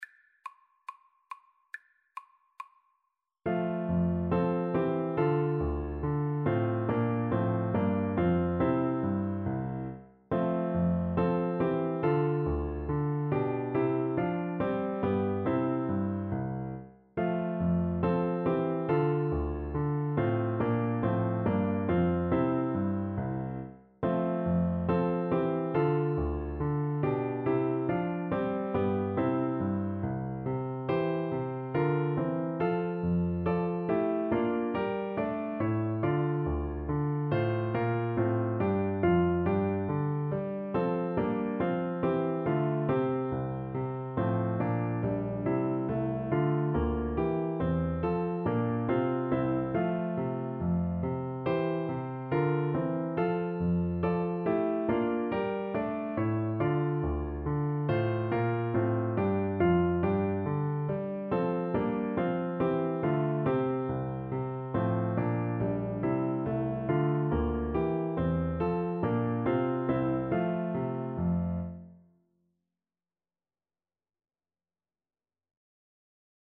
Classical (View more Classical Clarinet Music)
handel_gavotte_hwv491_CL_kar1.mp3